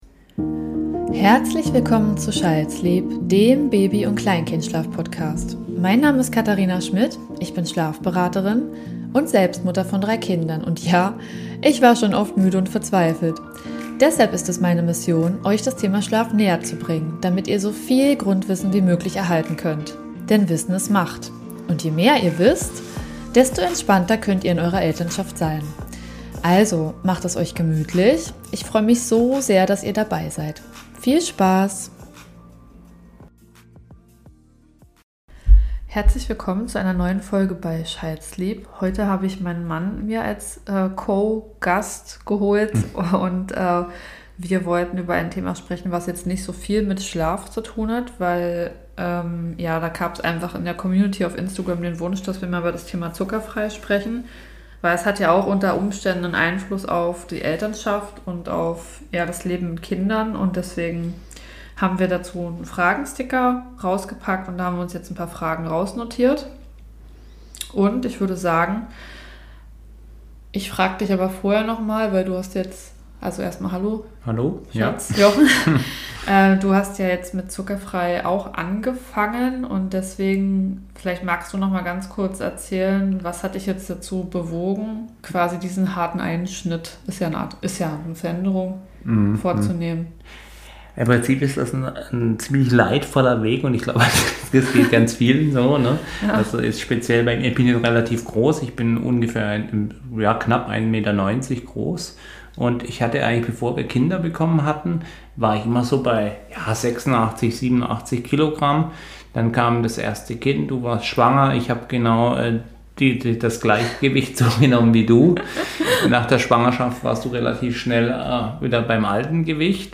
In dieser Folge spreche ich mit meinem Mann über unser Leben ohne Industriezucker . Wir beantworten eure Fragen und erzählen ehrlich, was sich dadurch wirklich verändert hat.